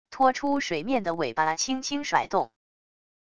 拖出水面的尾巴轻轻甩动wav音频